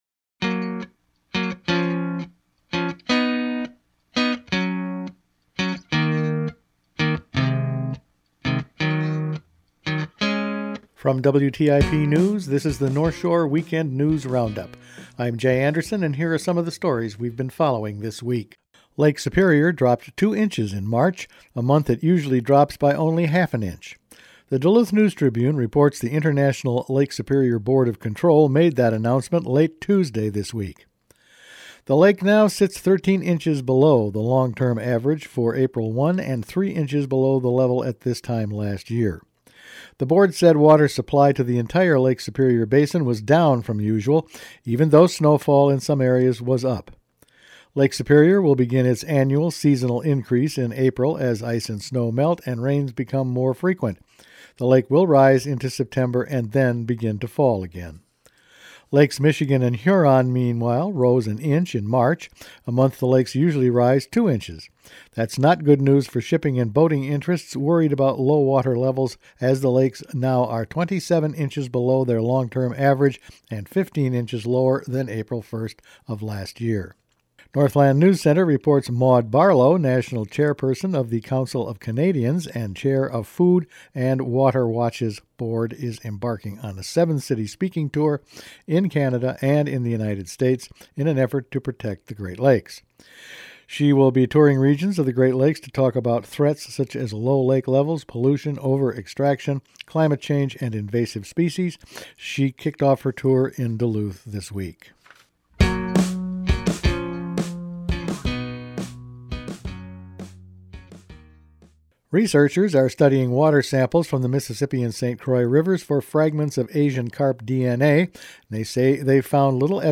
Each weekend WTIP news produces a round up of the news stories they’ve been following this week. Tall ships return to Duluth, Rep. Nolan faces gun advocates, No sign of Asian carp yet, and Lake Superior is way down…all in this week’s news.